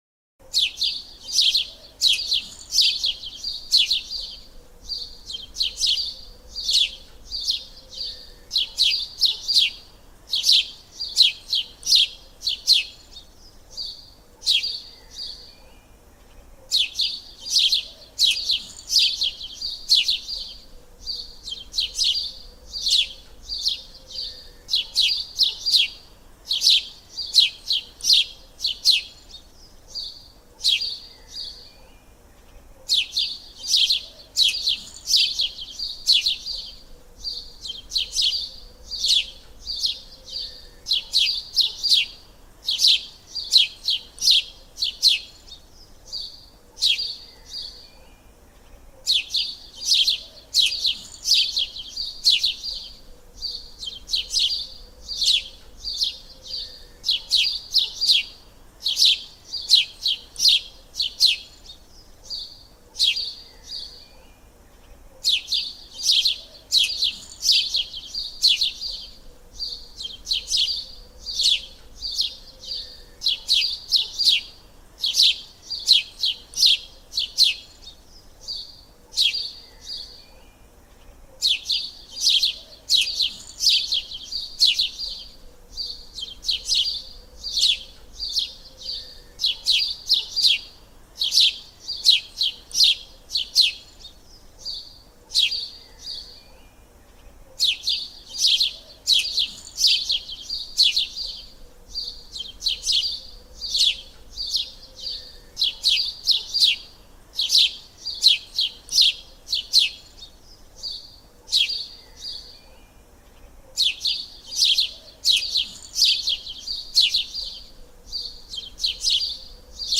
bruits-de-moineaux-chants-dun-moineau-oiseaux-dans-le-parc.mp3